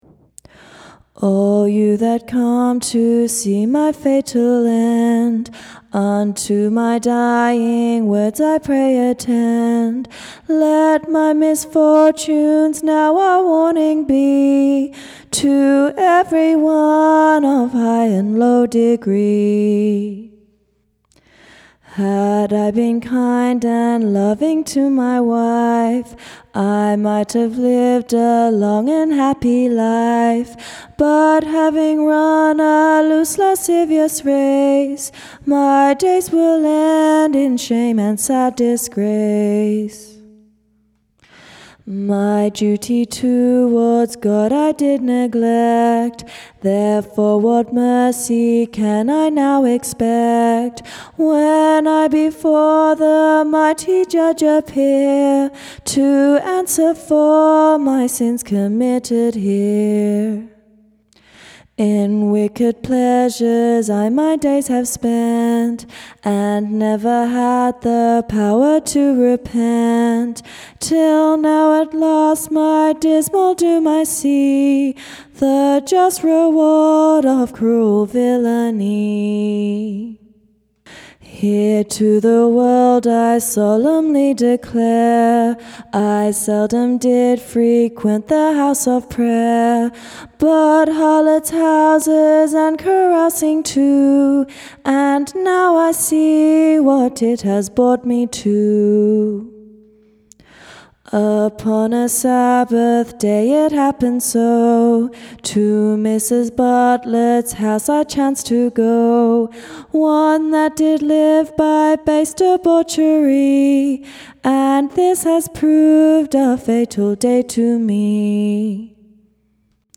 Execution Ballads